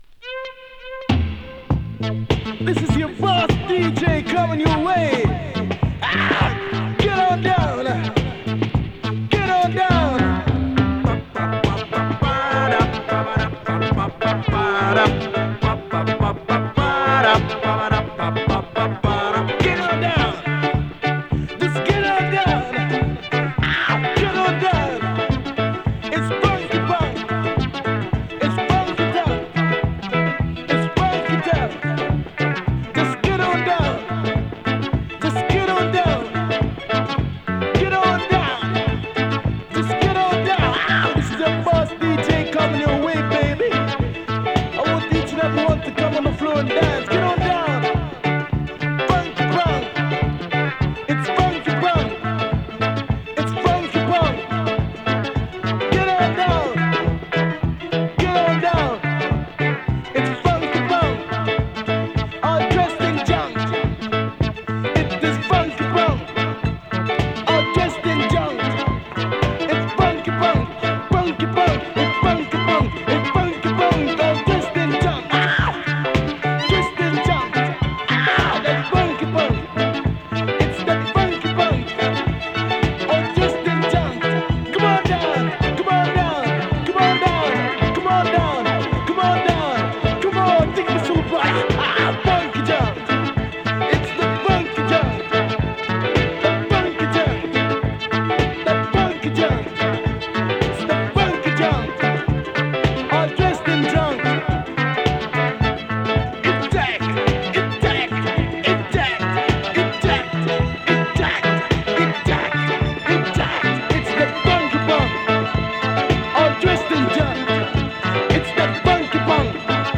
プレ ダンスホール トースティング入り ステッパーズ レゲエ
ダンスホールの原型とも言えるトースティングを聴かせるステッパーズ・レゲエ！